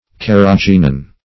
Carrageenan \Car`ra*geen"an\, Carrigeenin \Car`ri*geen"in\, n.